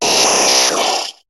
Cri d' Abra dans Pokémon HOME .